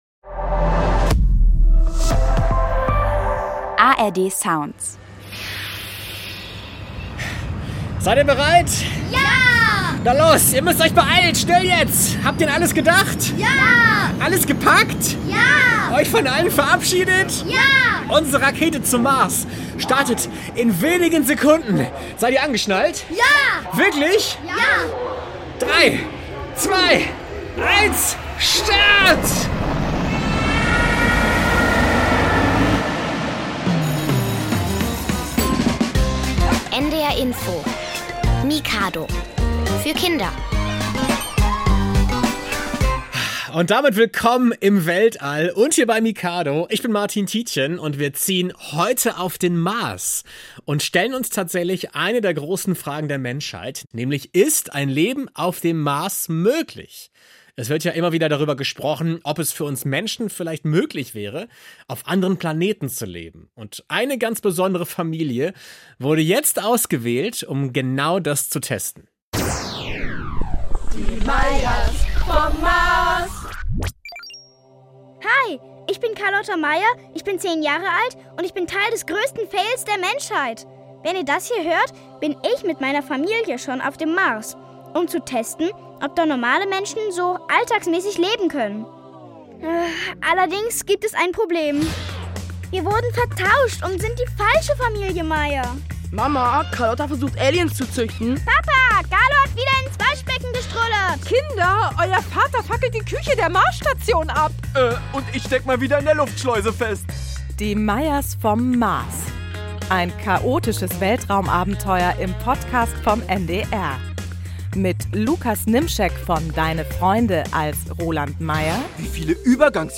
Außerdem gibt es gute Musik und eine Reportage von Kindern, die eine ganze Woche lang in einem echten Space-Camp verbracht haben.